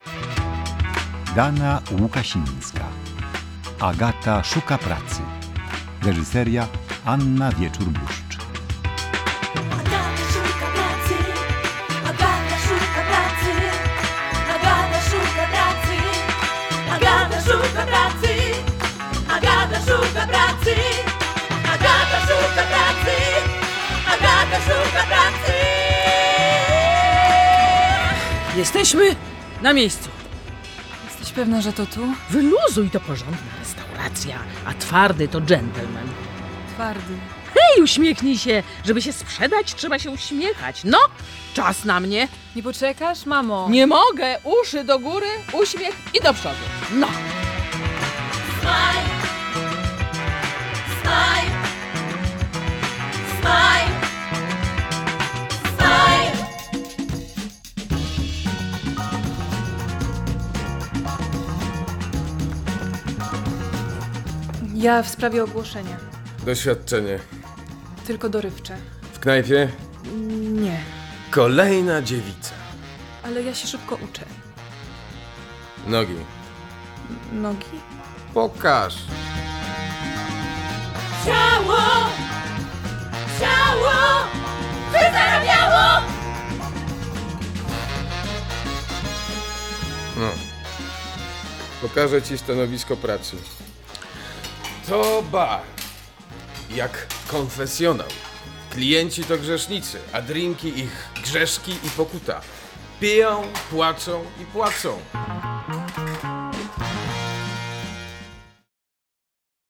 music: Budos Band
Radio adaptation of the play “Agata in Search of a Job”.